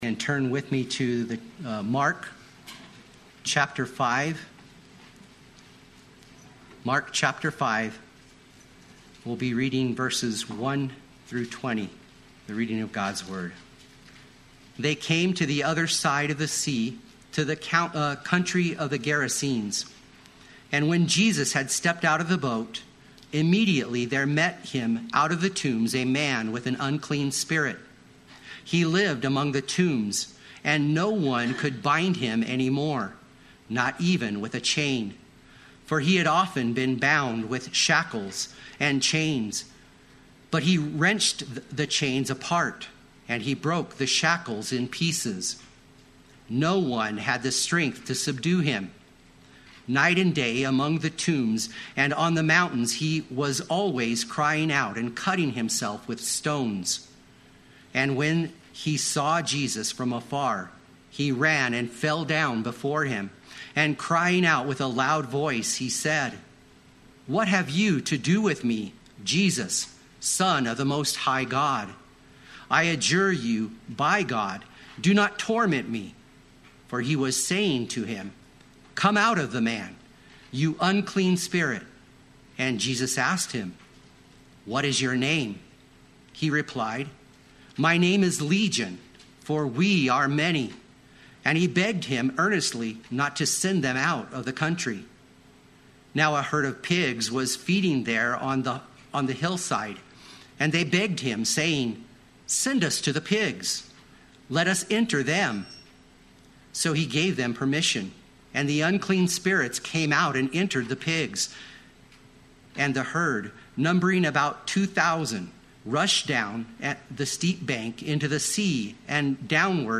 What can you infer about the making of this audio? Missions Sunday Sunday Worship